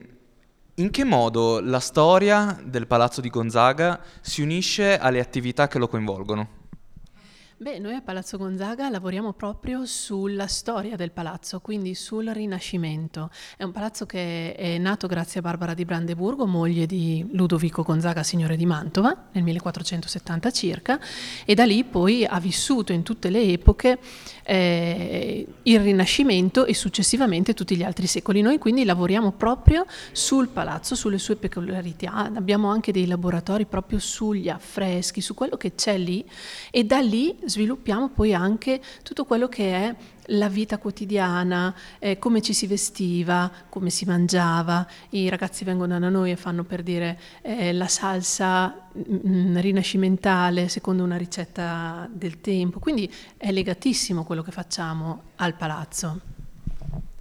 Ecco, ai nostri microfoni, le dichiarazioni raccolte durante la serata: